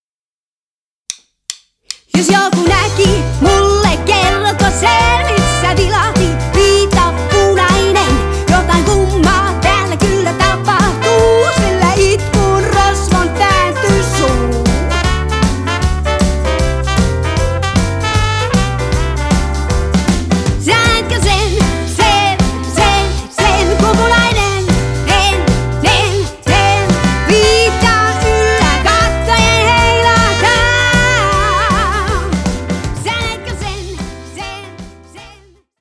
shuffle rock